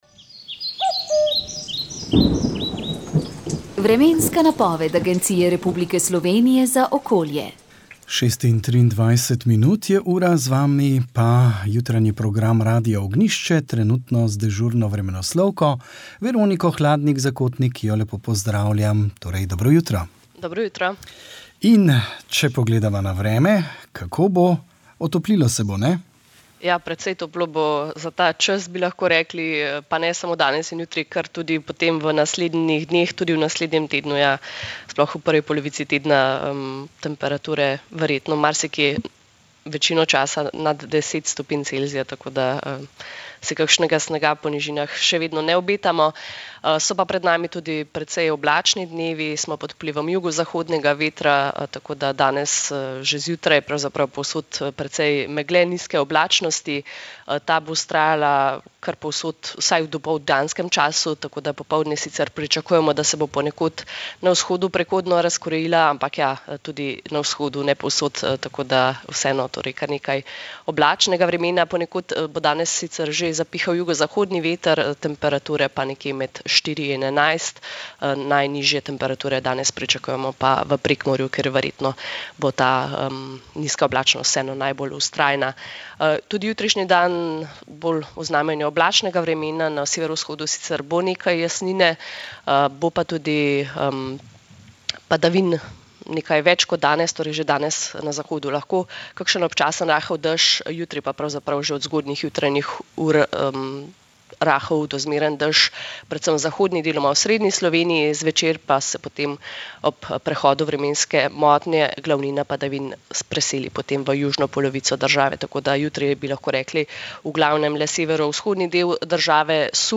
Informativni prispevki